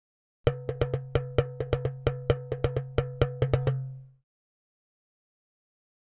Drums Short African Dance 2 - Fast